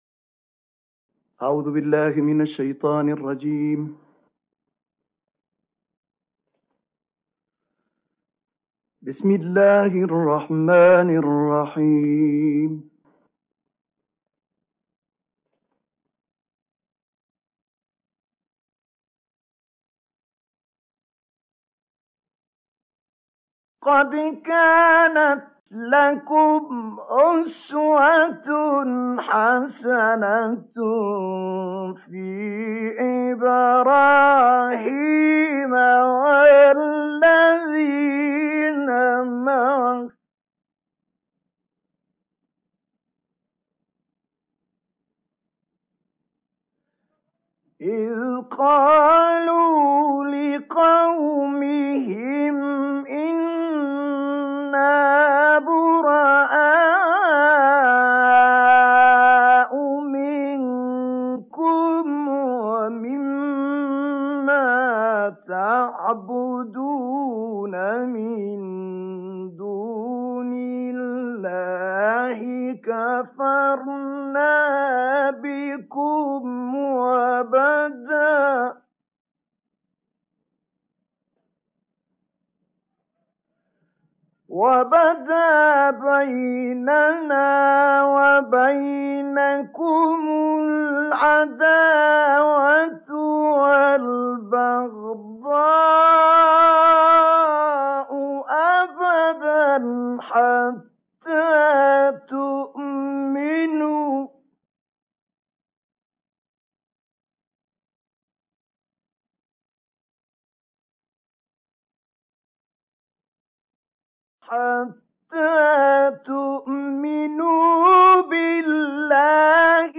قد كانت لكم أسوة حسنة في إبراهيم والذين معه - تلاوة من سورة الممتحنة
تلاوات خاشعة للشيخ طه الفشنى